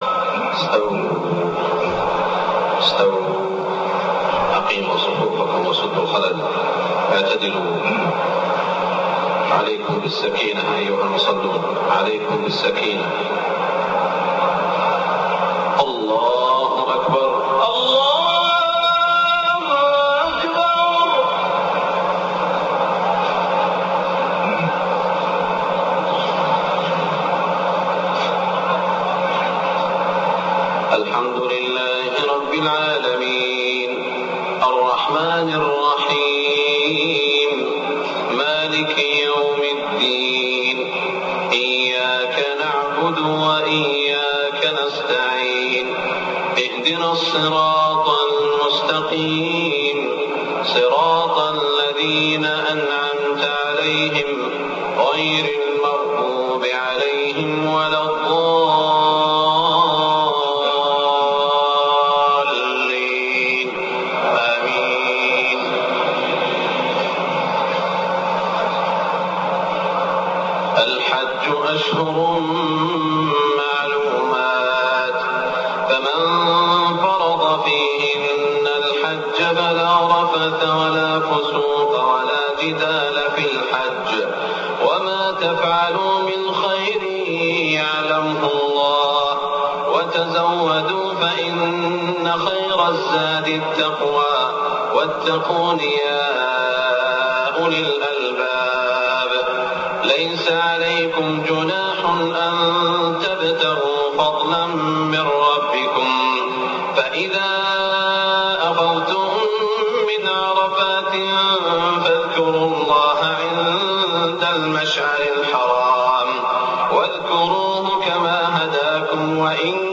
صلاة العشاء موسم الحج 1424هـ من سورة البقرة > 1424 🕋 > الفروض - تلاوات الحرمين